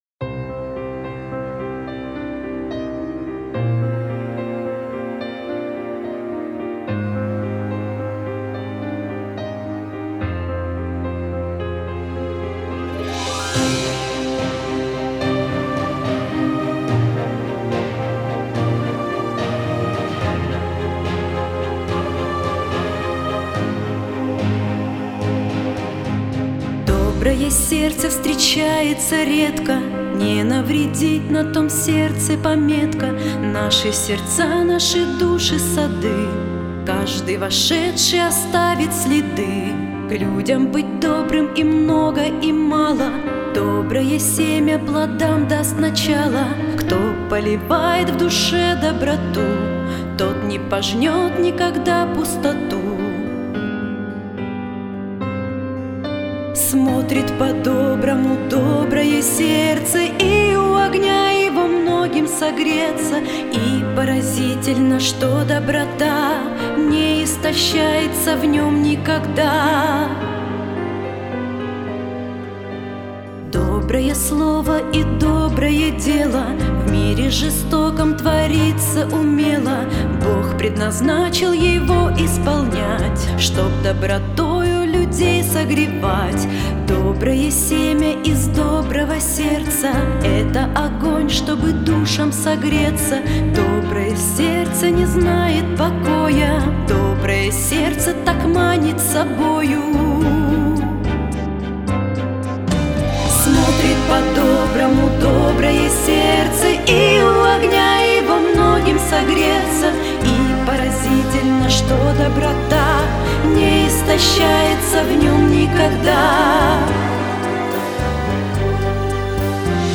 591 просмотр 602 прослушивания 69 скачиваний BPM: 76